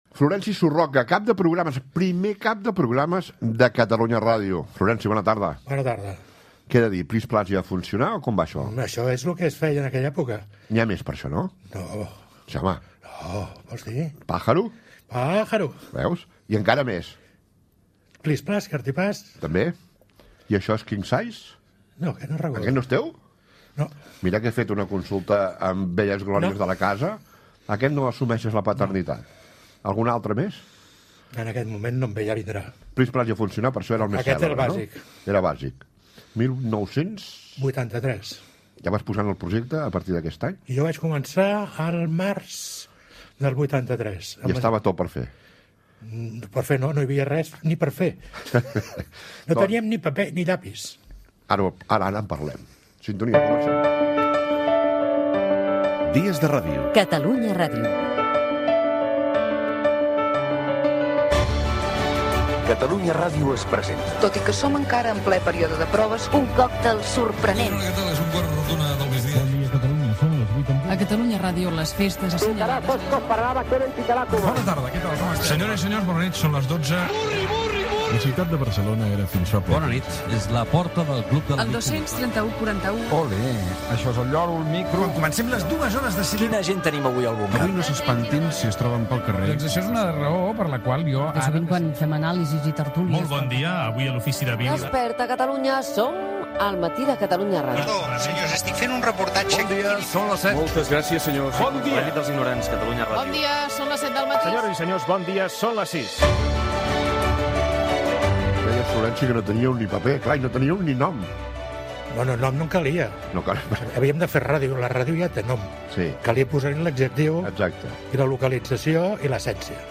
Primera pregunta, careta del programa i entrevista